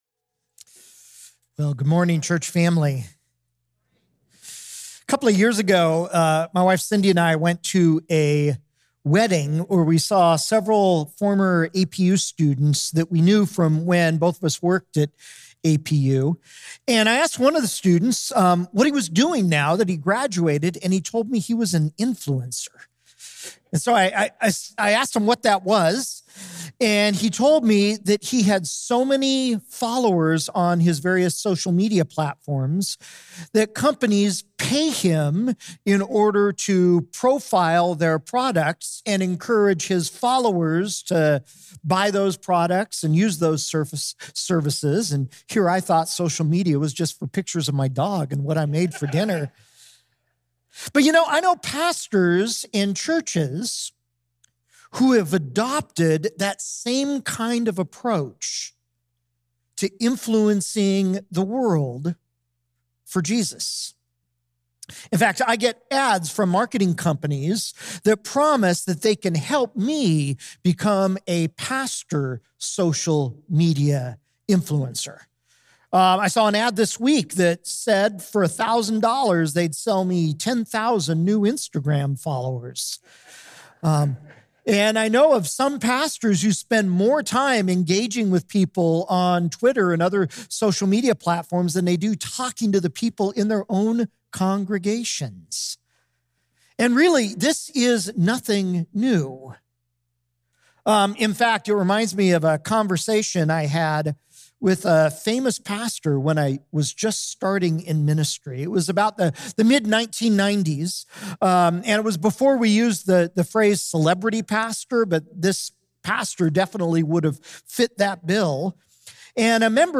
September 10, 2023 – Re-Forming our Influence (Message Only) – Glenkirk Church